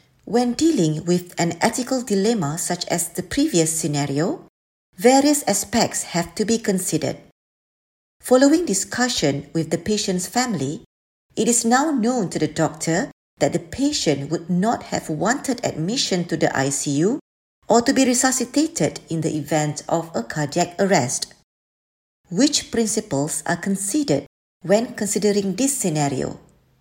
Narration audio (MP3) Contents Home What is Medical Ethics?